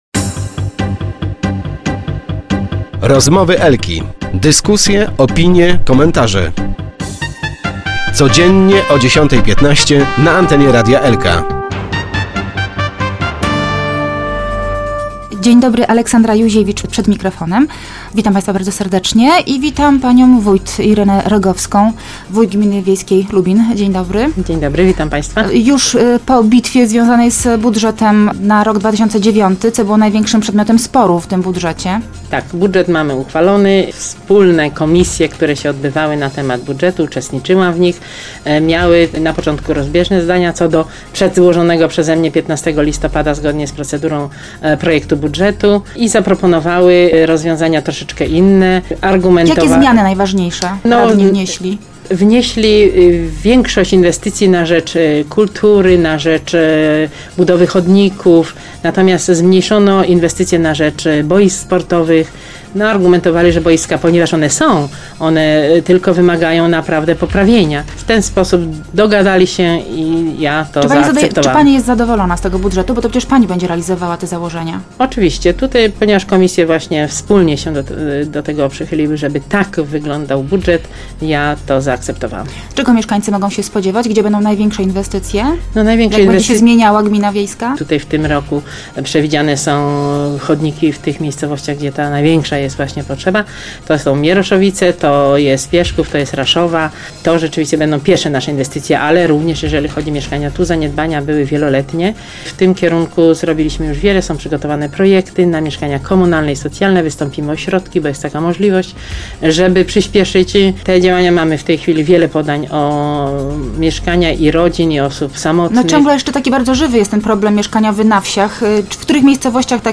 Gmina wiejska w tym roku zrealizuje szereg inwestycji, które w bezpośredni sposób usprawnią życie mieszkańcom gminy wiejskiej. - W planach mamy także budowę mieszkań komunalnych - poinformowała wójt gminy wiejskiej Lubin Irena Rogowska w Rozmowach Elki.